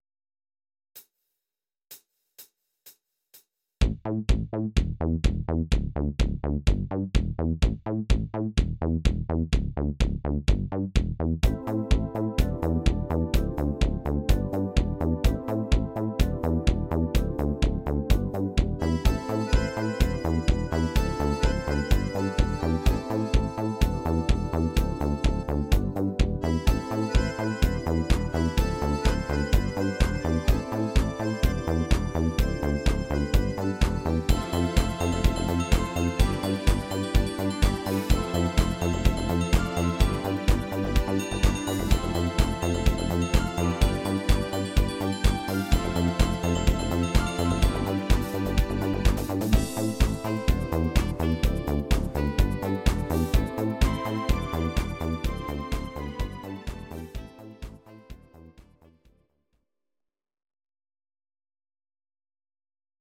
Audio Recordings based on Midi-files
Ital/French/Span, 1980s